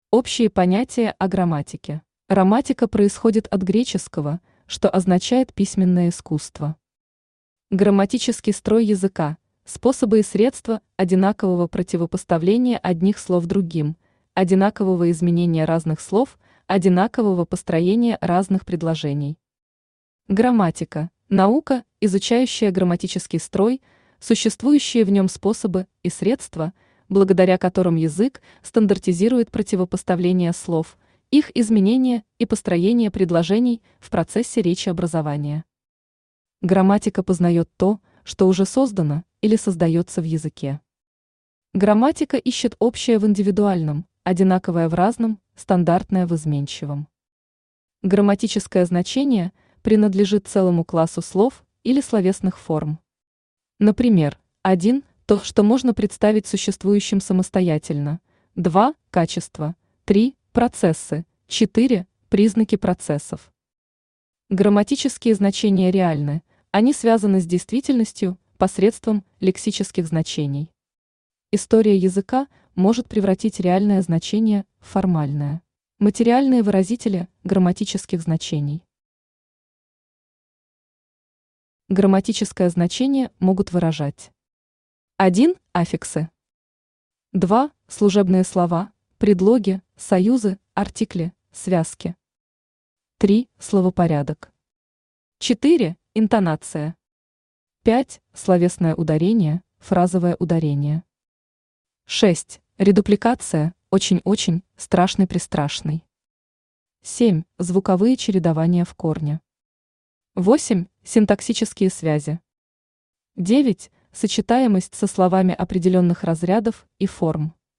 Аудиокнига Элементарная теория грамматики. Конспективное изложение | Библиотека аудиокниг
Читает аудиокнигу Авточтец ЛитРес.